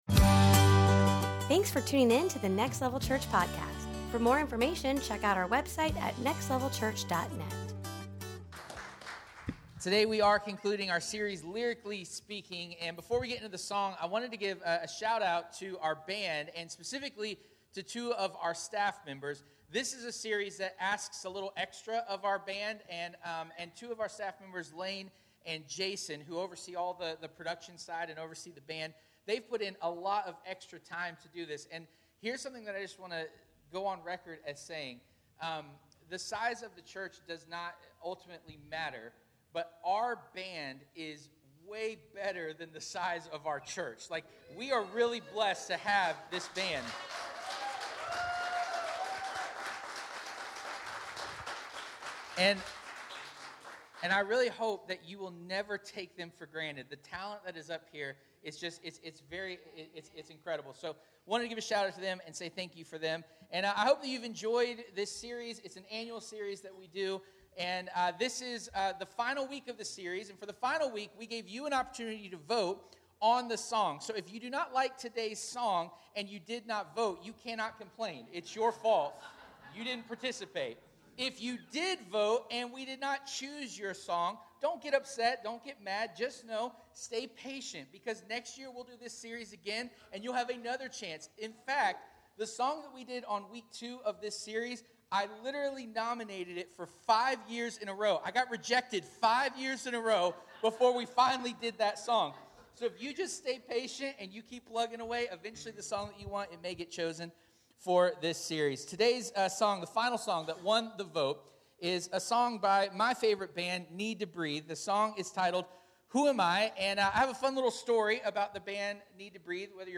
Lyrically Speaking 2022 Service Type: Sunday Morning « Lyrically Speaking 2022